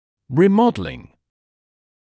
[ˌriː’mɔdəlɪŋ][ˌриː’модэлин]ремоделирование